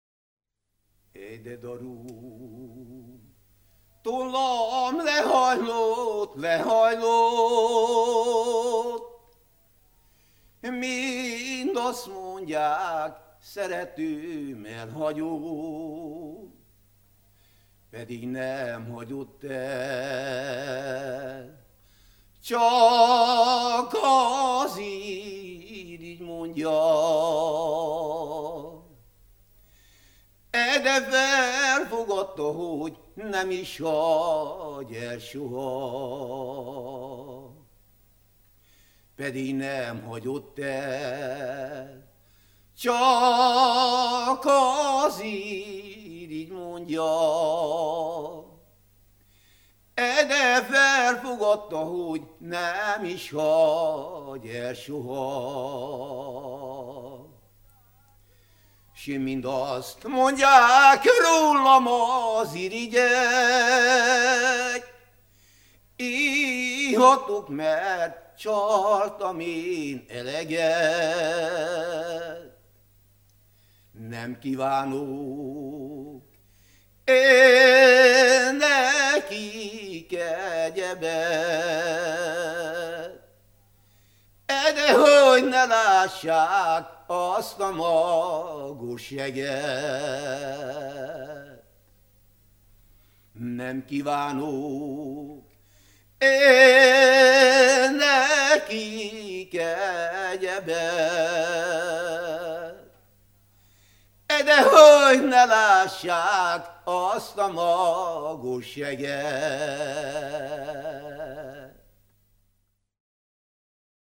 ének
Nádas mente (Kalotaszeg, Erdély)